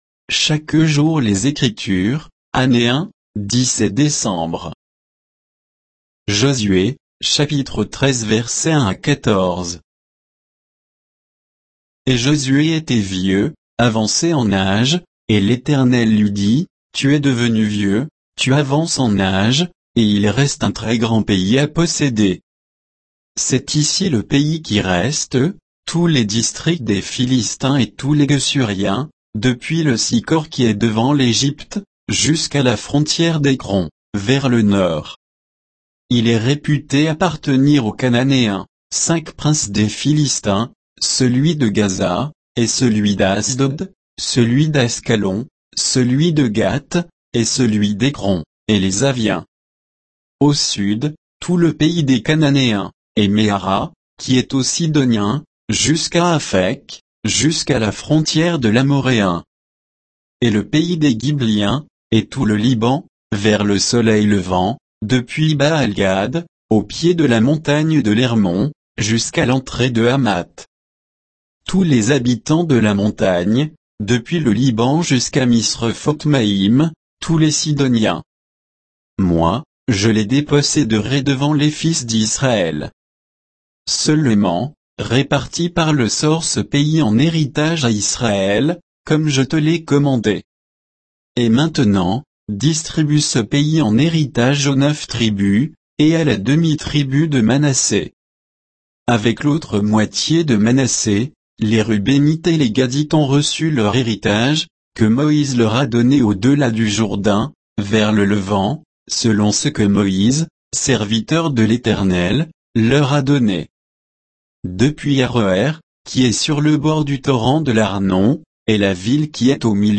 Méditation quoditienne de Chaque jour les Écritures sur Josué 13, 1 à 14